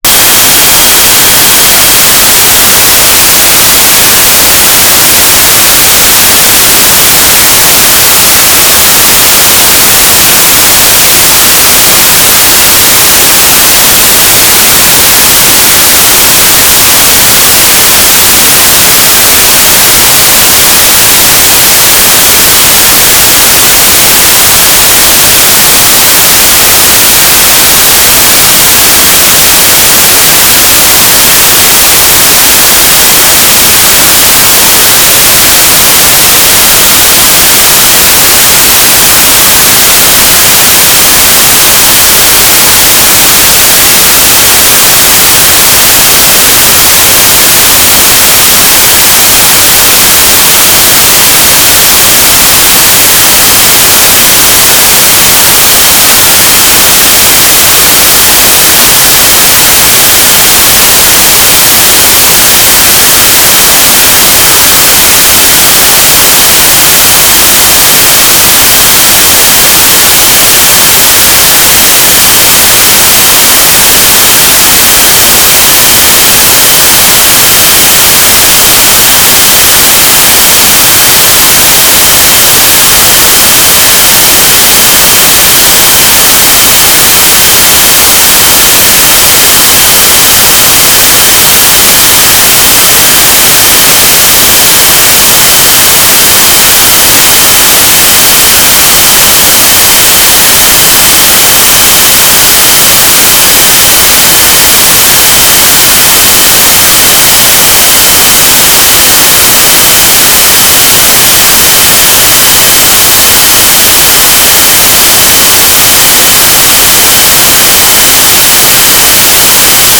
"transmitter_description": "Mode U - GFSK38k4",